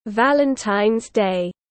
Ngày lễ tình yêu tiếng anh gọi là Valentine’s day, phiên âm tiếng anh đọc là /ˈvæl.ən.taɪnz ˌdeɪ/